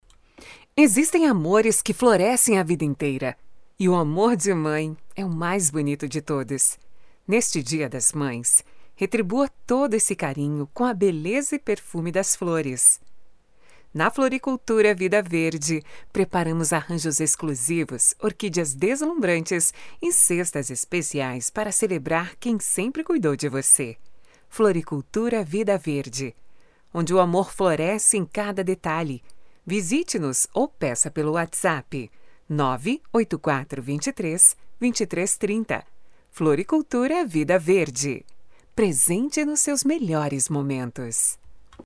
LOC DIA DAS MÃES FLORICULTURA: